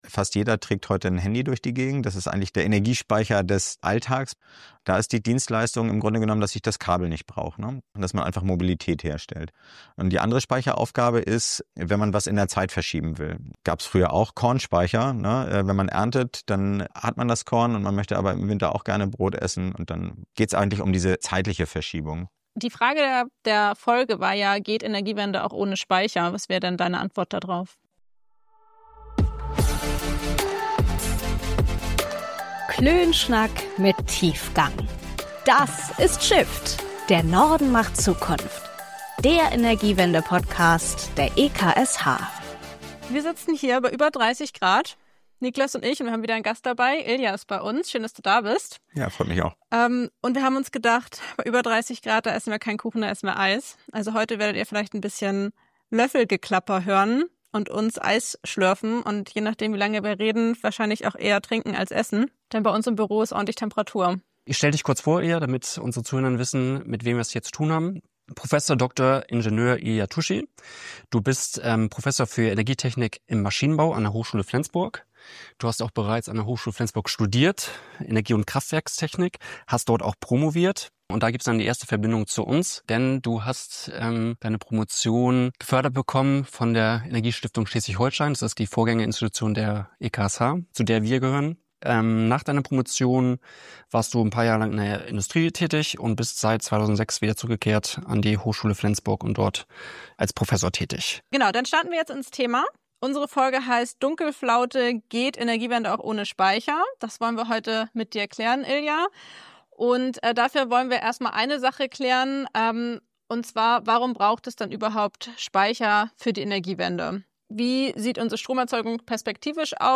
Viel Spaß bei unserem Klönschnack mit Tiefgang!